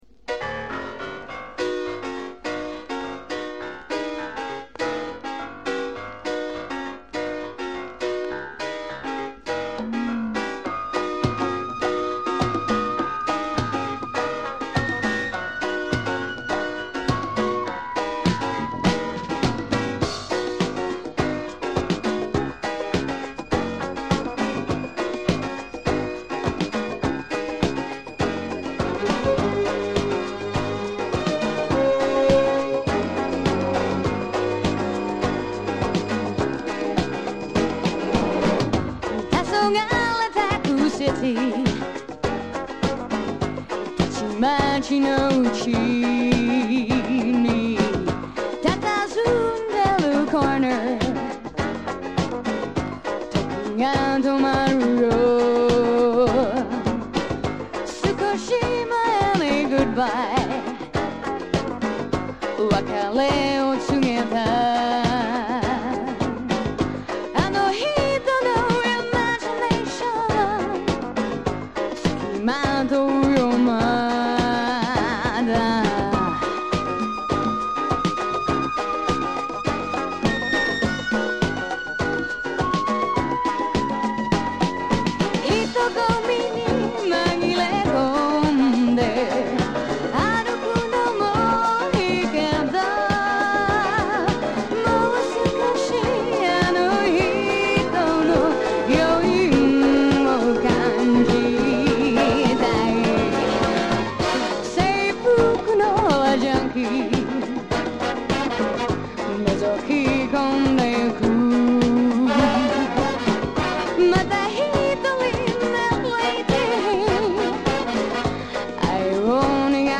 アメリカ人と日本人のハーフ歌手で美貌と歌唱力を
和製ディスコ